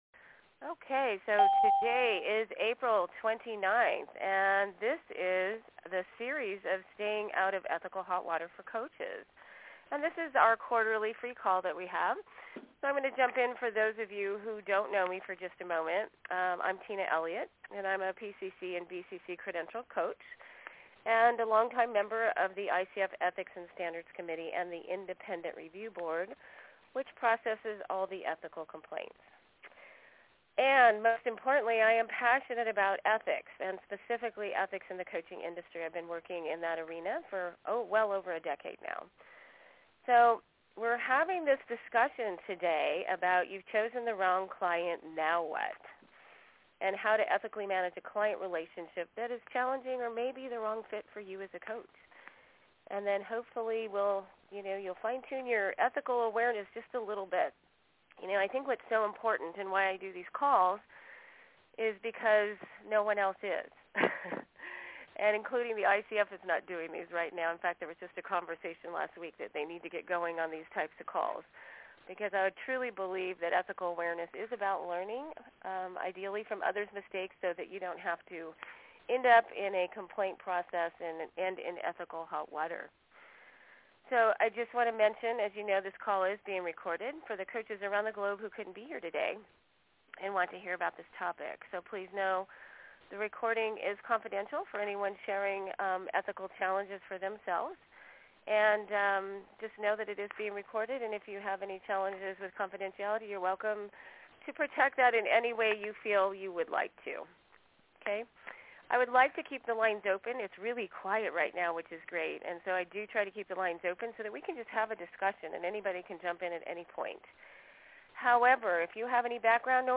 A discussion on how to ethically manage a client relationship that is challenging or the wrong fit for you as a coach.